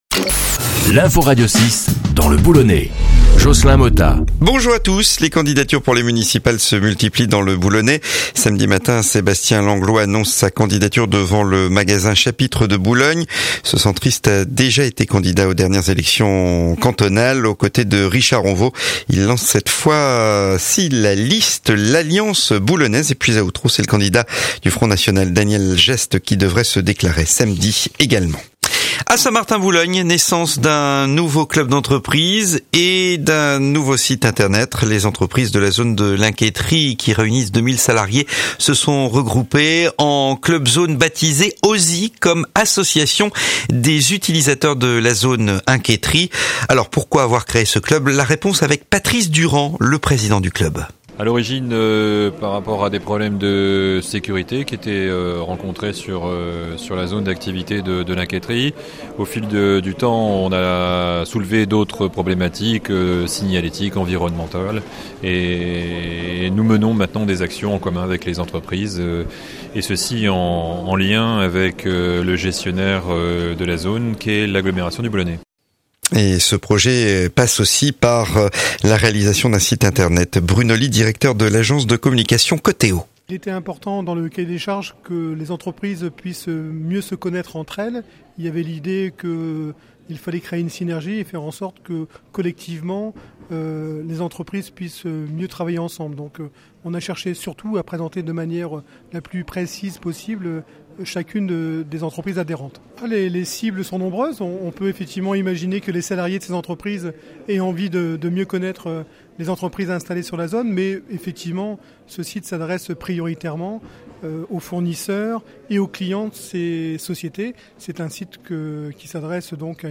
Le journal dans le boulonnais du vendredi 4 octobre